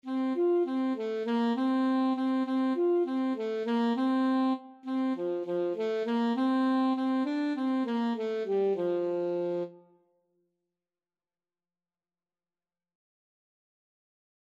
World Trad. Pimpon (SpanishTraditional) Alto Saxophone version
Traditional Music of unknown author.
F major (Sounding Pitch) D major (Alto Saxophone in Eb) (View more F major Music for Saxophone )
Quick
2/4 (View more 2/4 Music)
F4-F5
Spanish